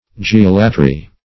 Geolatry \Ge*ol"a*try\, n. [Gr. ge`a, gh^, the earth + ?